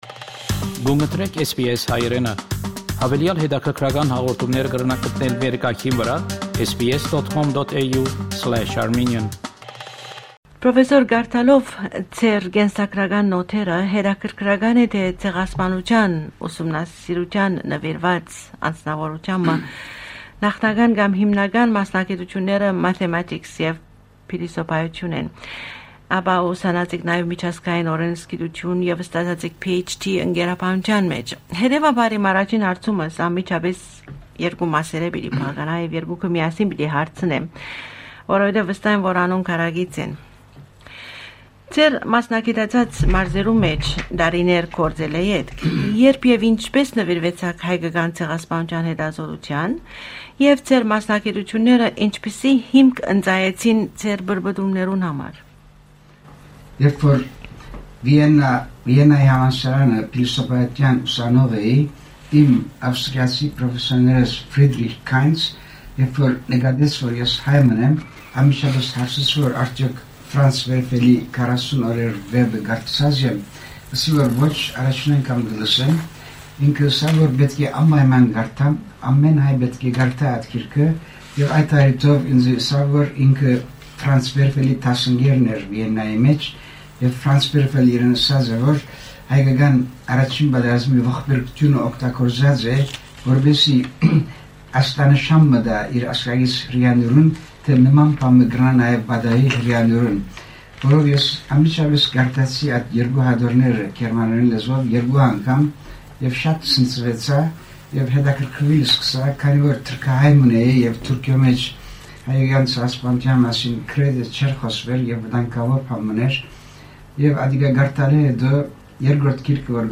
Այս հարցազրոյցին մէջ, որ ձայնագրուած է 1996-ին Սիտնիի SBS ռատիօկայանին մէջ